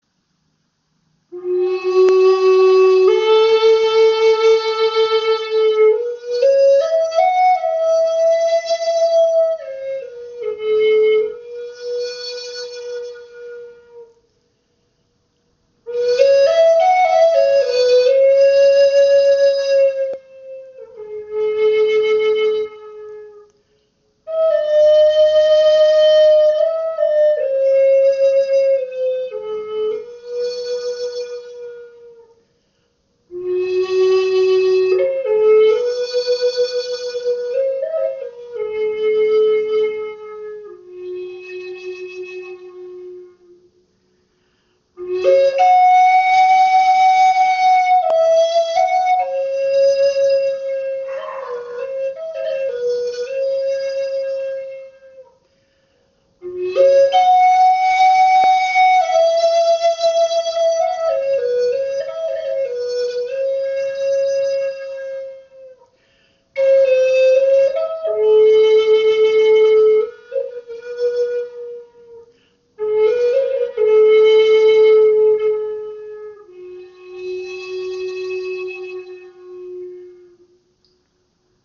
Klangbeispiel
Diese Flöte hat einen angenehmen Luftwiderstand und lässt sich einfach modulieren.
Gebetsflöte in F